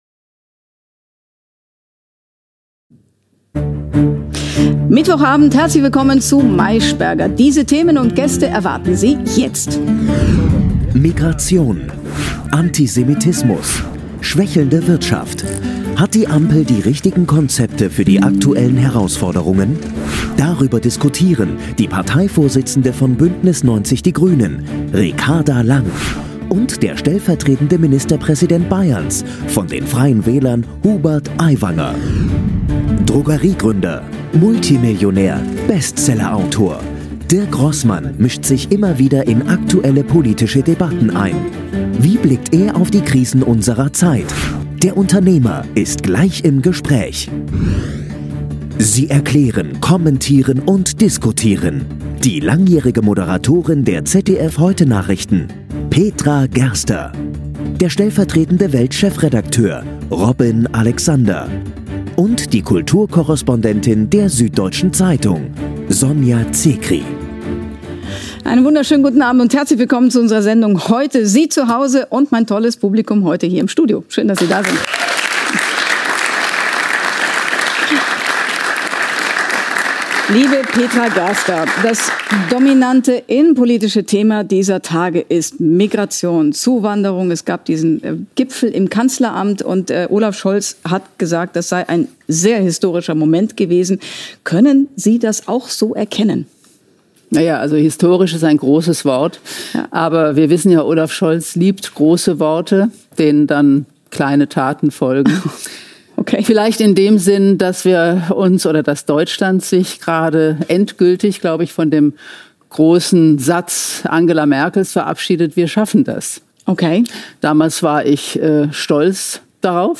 Der Talk bei Google
Der wieder ins Kabinett Söder berufene Wirtschaftsminister Hubert Aiwanger (52, Freie Wähler) hat in seiner ersten Talkshow nach der Bayern-Wahl neue Angriffe auf seine Kritiker in den Medien gestartet.